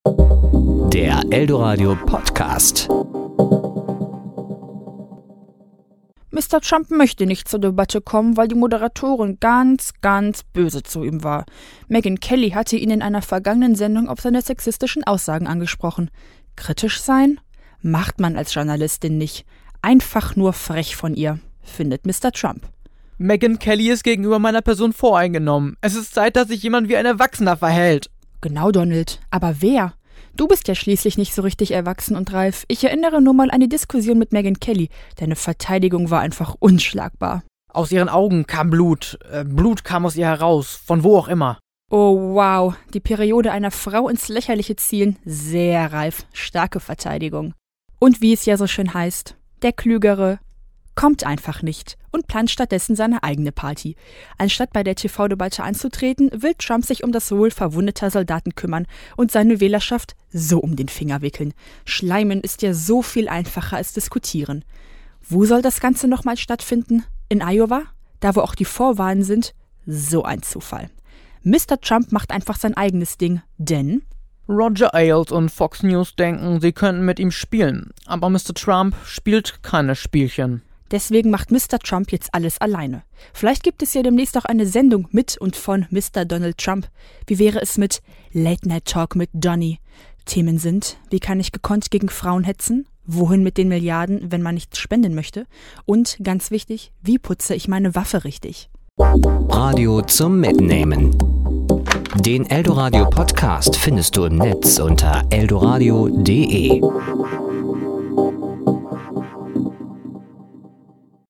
Serie: Glosse